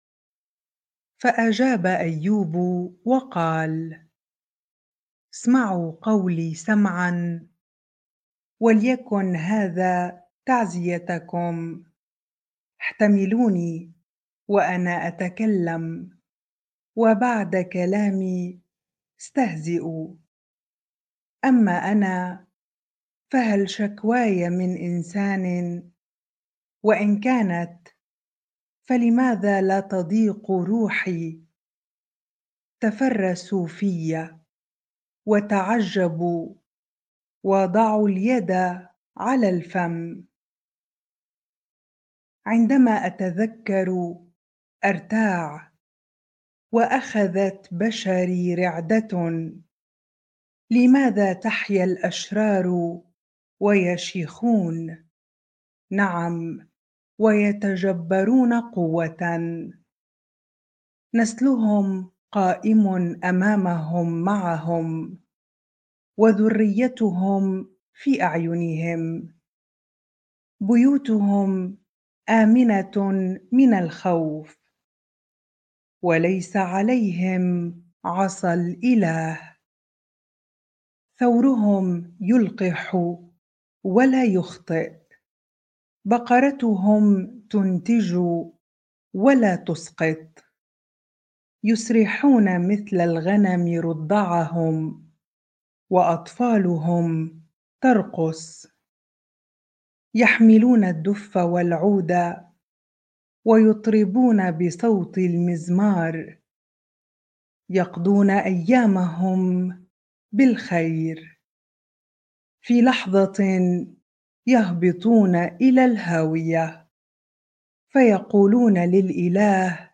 bible-reading-Job 21 ar